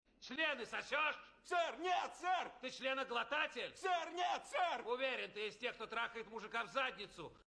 chleny sosesh ser net ser Meme Sound Effect